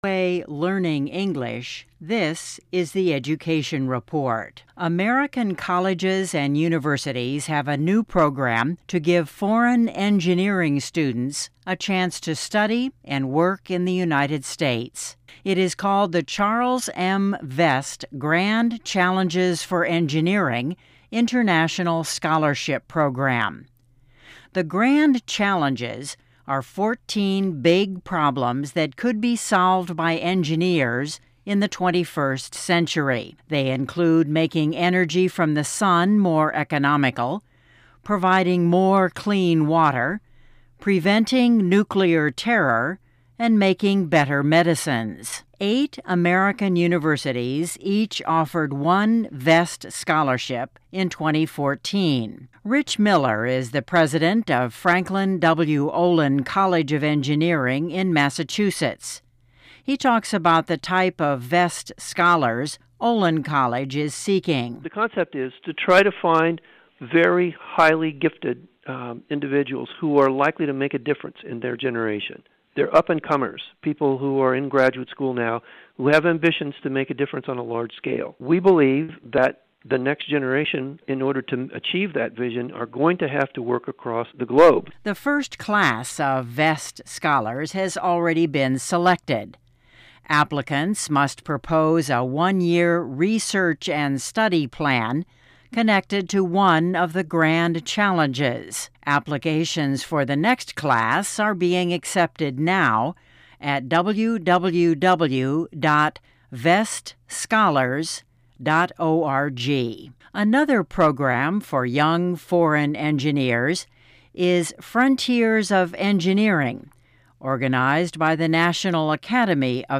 Learn English as you read and listen to a weekly show about education, including study in the U.S. Our stories are written at the intermediate and upper-beginner level and are read one-third slower than regular VOA English.